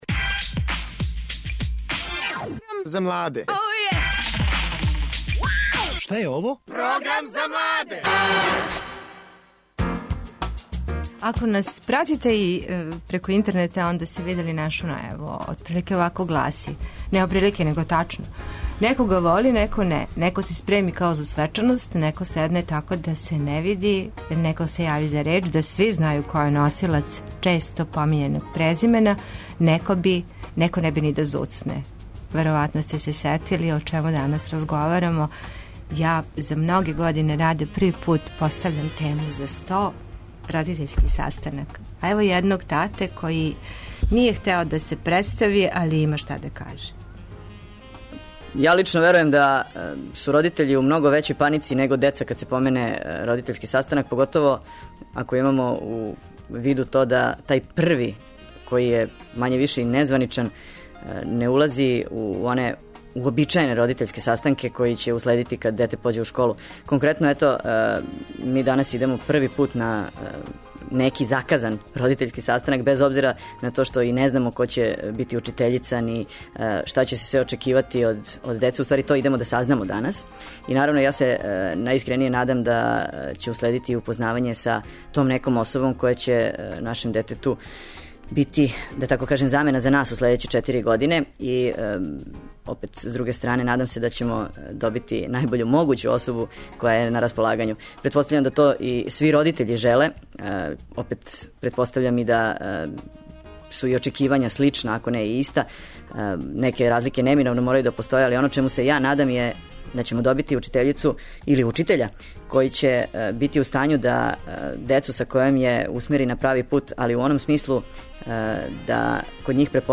Родитељски састанак! Говоре родитељи, ученици, директорка...